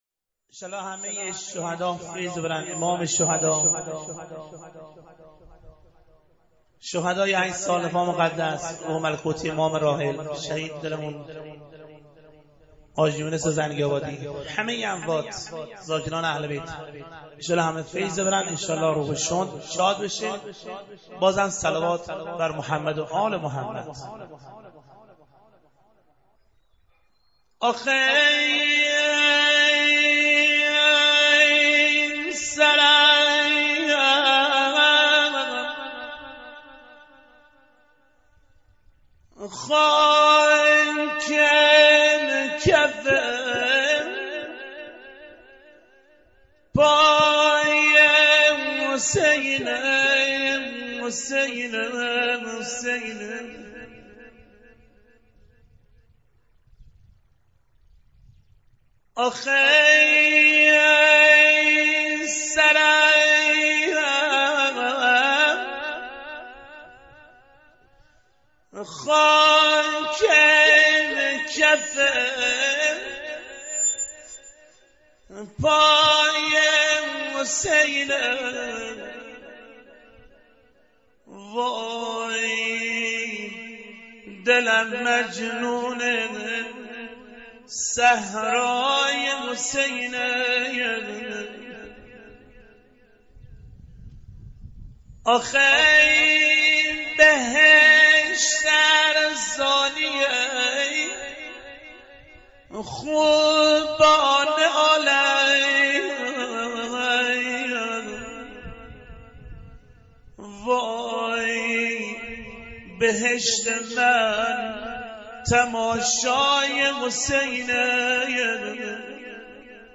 زیارت عاشورای حرم مطهر شهدای شهر زنگی آباد 93/08/01
مداحی